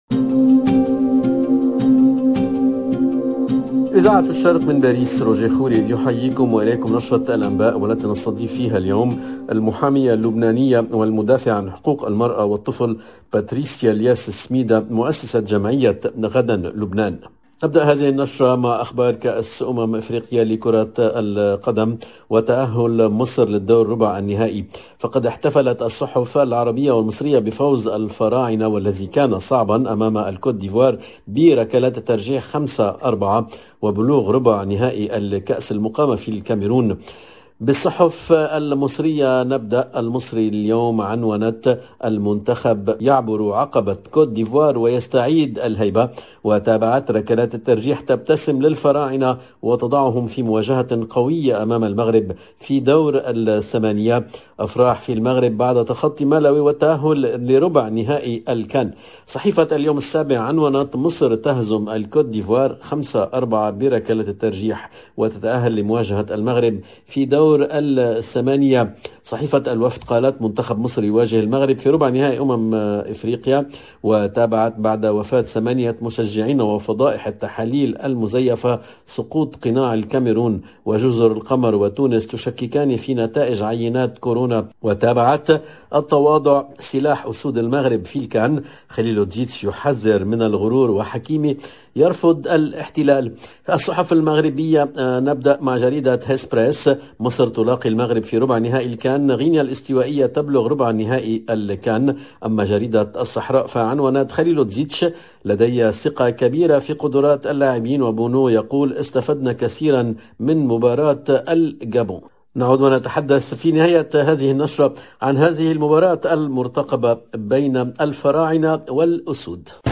LE JOURNAL DE MIDI 30 EN LANGUE ARABE DU 27/01/22